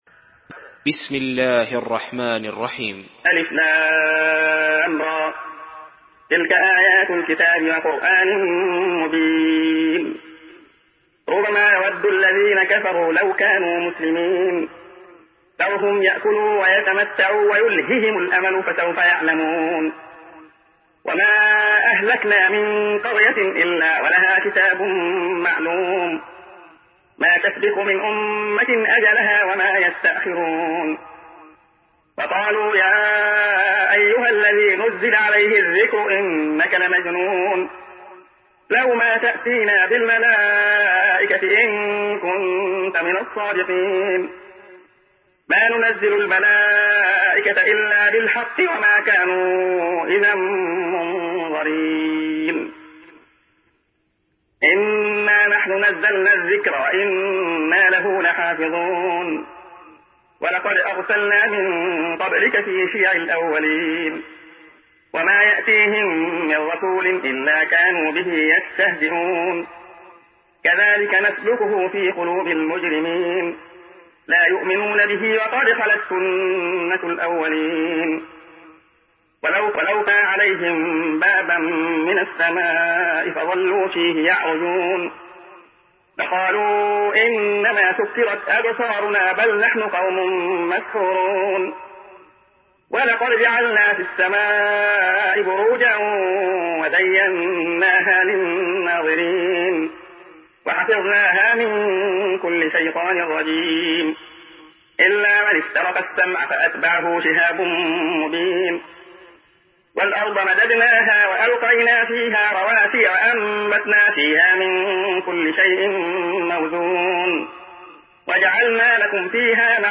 Surah Hicr MP3 by Abdullah Khayyat in Hafs An Asim narration.
Murattal Hafs An Asim